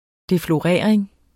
deflorering substantiv, fælleskøn Bøjning -en Udtale [ defloˈʁεˀɐ̯eŋ ] Betydninger 1. det at have samleje med nogen som den første Synonym defloration sexscenerne fylder meget i bogen ..